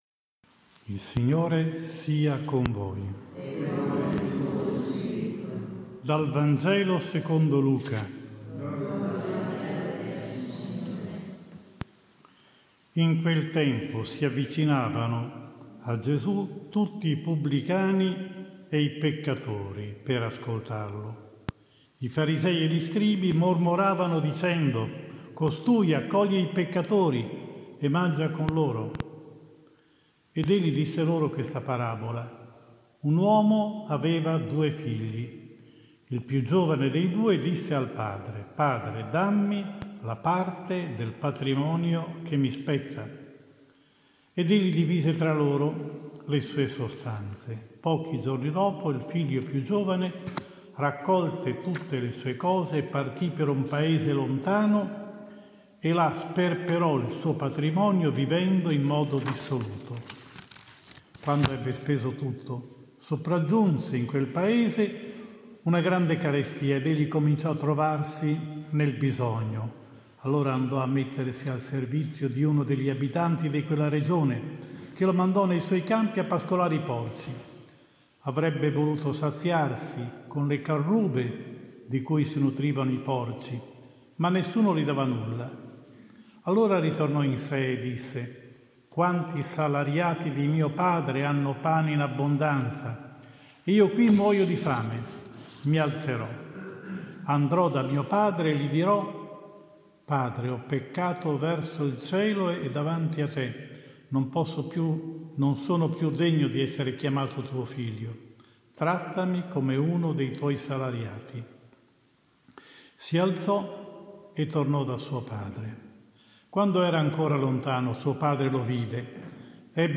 27 Marzo 2022 IV DOMENICA DI QUARESIMA – LAETARE (Anno C): omelia